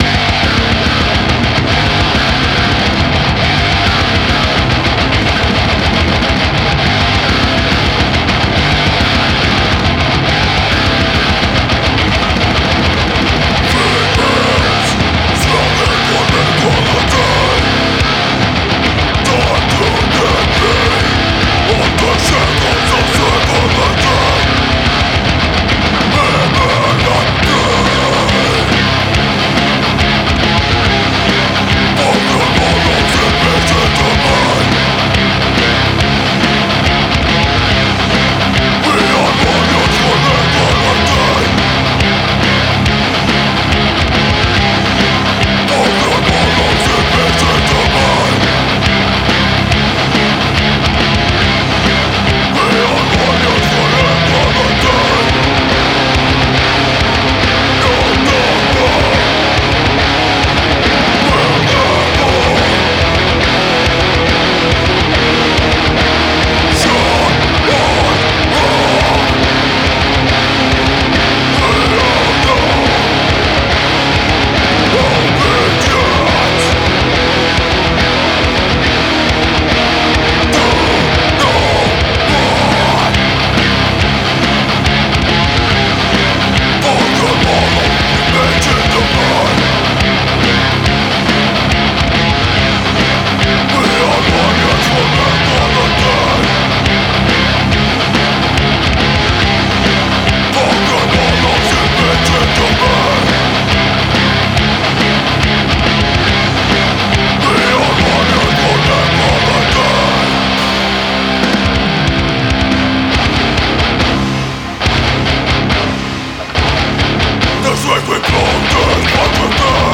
Ich habe nochmal eine Version mit einem anderen, etwas konventionelleren Gitarrensound hochgeladen (diesmal nur Tonex für die Rhythmus-Gitarren). Da hörst du auch wieder, dass der Mix sehr viel präsenter und direkter klingt als bei dir bisher, was einerseits u.a. entscheidend am subtileren Reverb-Mix liegt und auch an den präsenteren Rhythmus-Gitarren.